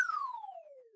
dm_received.ogg